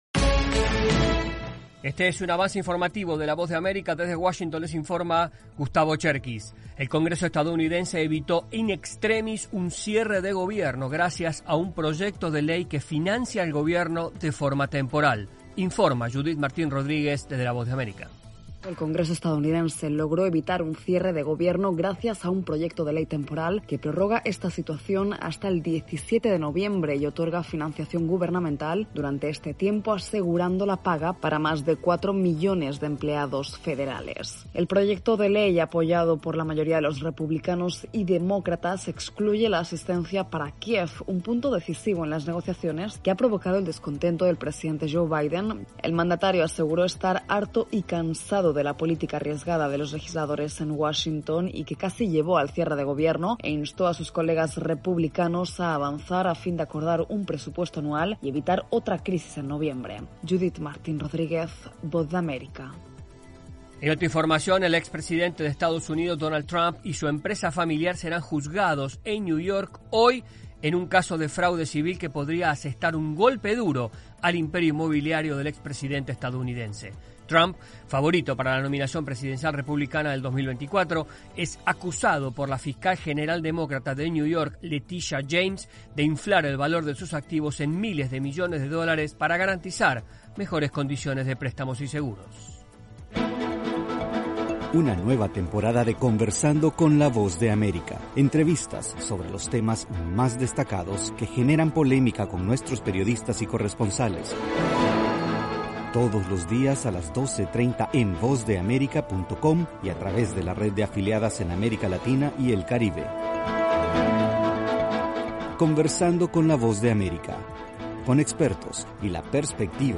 Avance Informativo 9:00AM
Este es un avance informativo de la Voz de América.